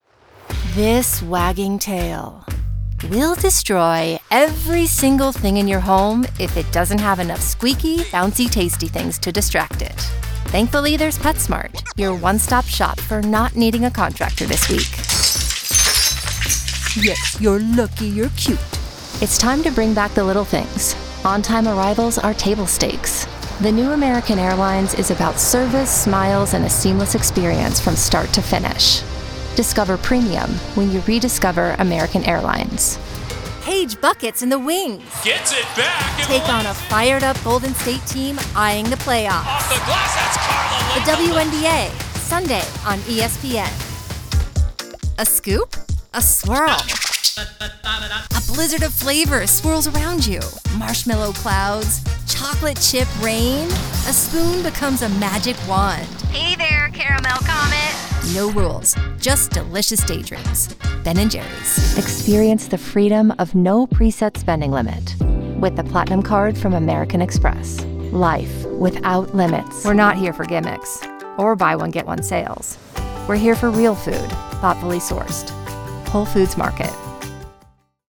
Versatile voice over artist delivering professional narration, commercials, eLearning, and character voices with clear, engaging audio for any project.
Professional Demo